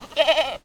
goat_call_02.wav